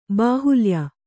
♪ bāhulya